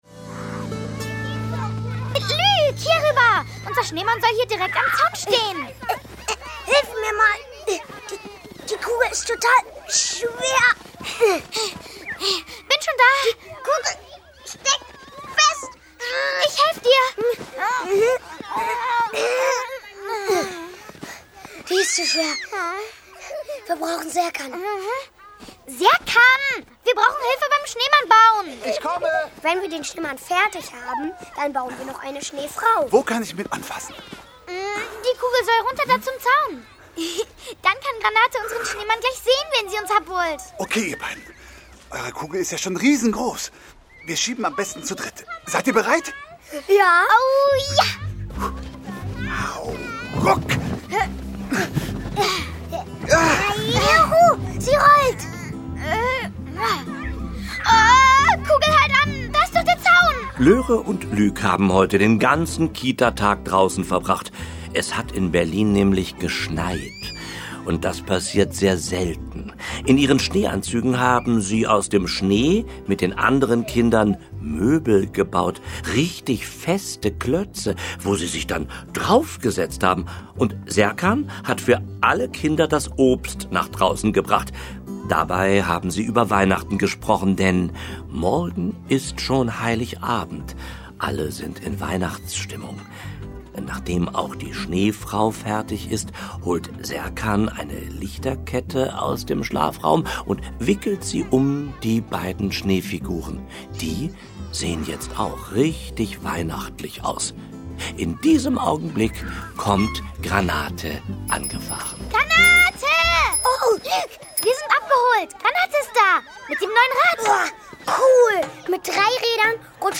Hörspiele mit Ilja Richter u.v.a. (1 CD)
Schlagworte Berlin • BIPOC • Conni • divers • Fasching • Fuchsbande • Hörspiel • Hörspielserie • Kinder ab 3 • Kindergartenkind • Kinderhörspiel • Kita • Lauras Stern • Löre und Luc • Multikulti • Neuerscheinung 2022 • Selbständigkeit • Urban • Weihnachten